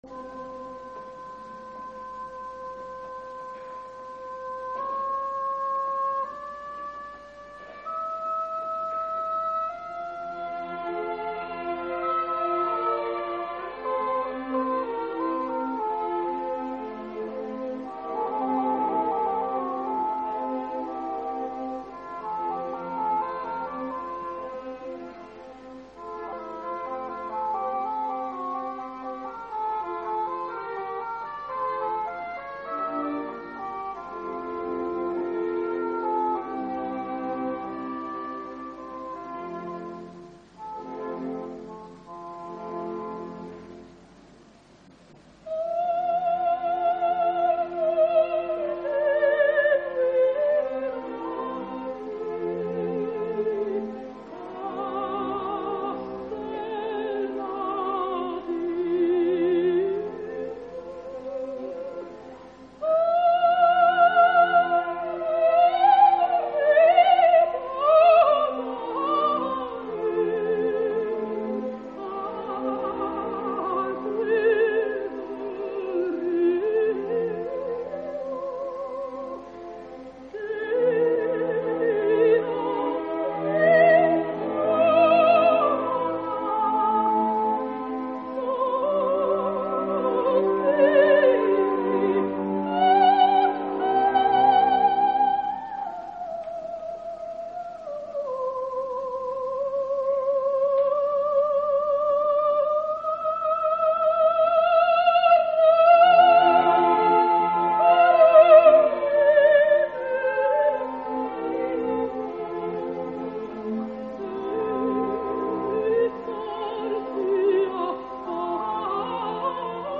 at Teatro alla Scala, Milano
conducted by G. Gavazzeni
Teatro alla Scala Orchestra & Chorus
Giovanna Seymour : G. Simionato
Lord Riccardo Percy : G. Raimondi
Henrico VIII : N. Rossi-Lemeni
Live performance
anna_live.mp3